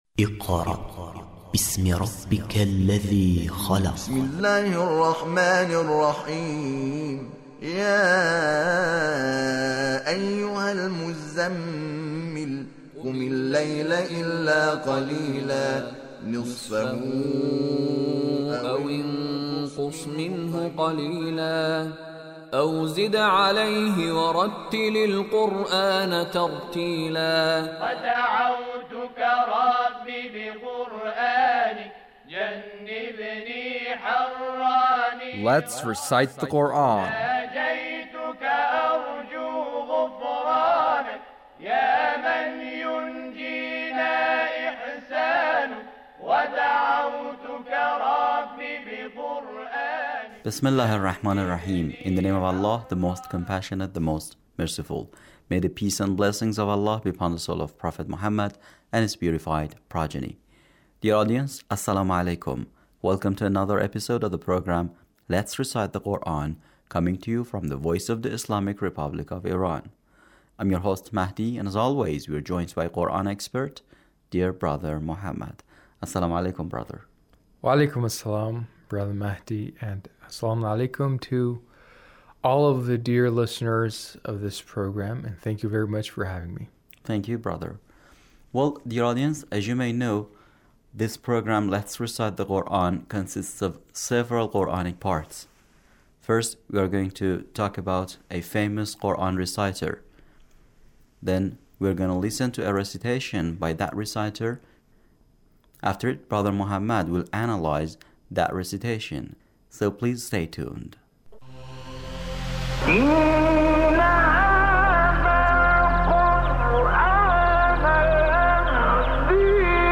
Recitation of Sheikh Ahmed Nuayna